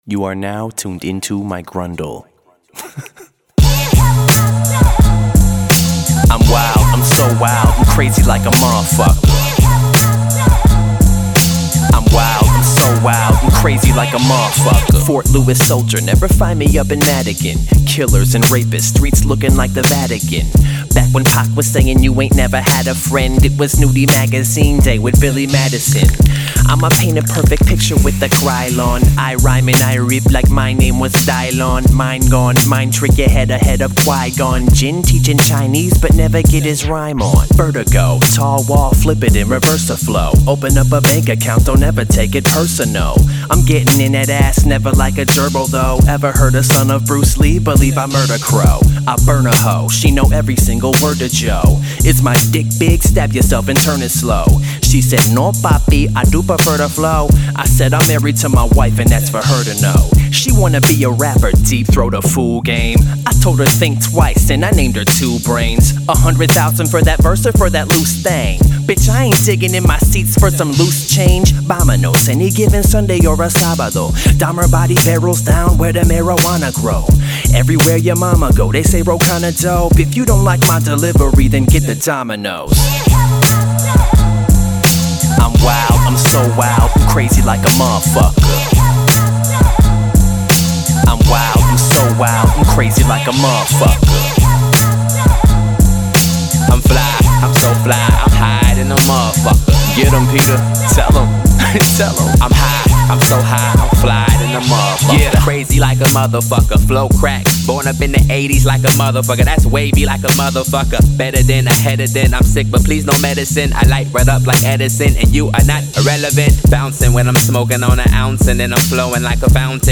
talk some smooth shit